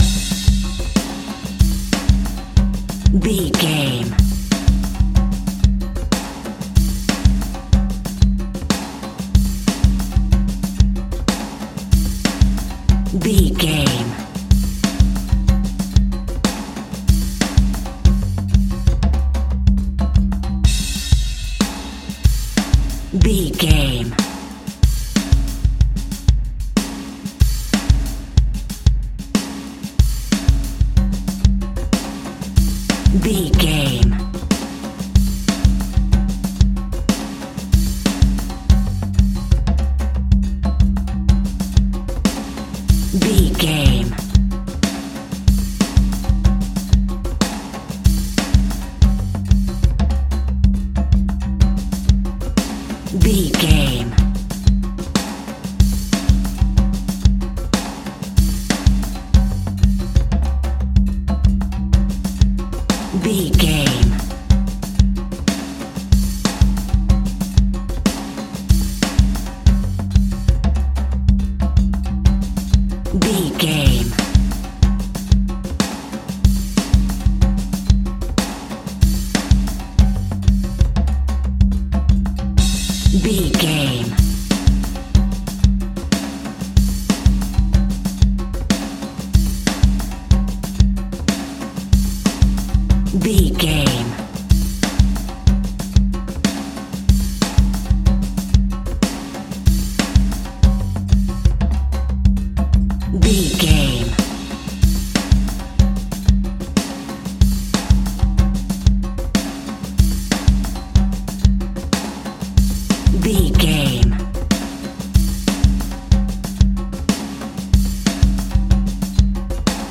Aeolian/Minor
latin
uptempo
bass guitar
percussion